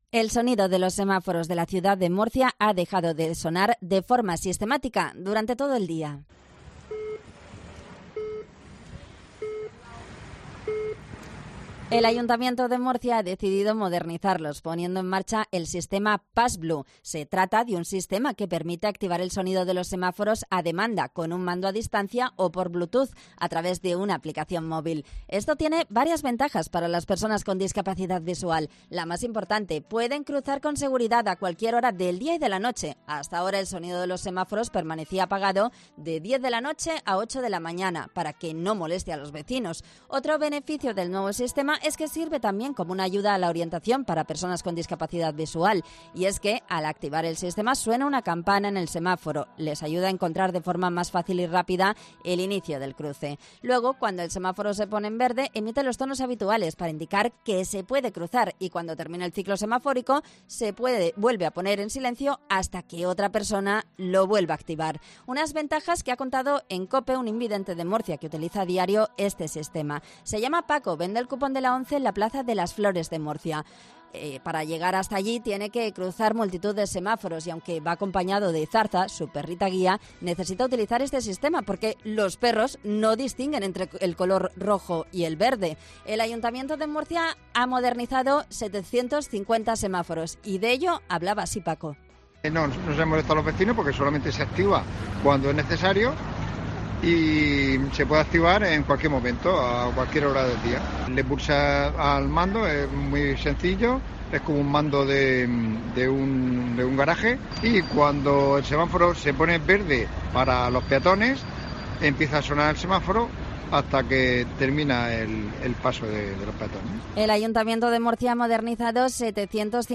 Y es que al activar el sistema suena una campana en el semáforo, que les ayuda a encontrar de forma más fácil y rápida el inicio del cruce.
Así utilizan las personas invidentes este sistema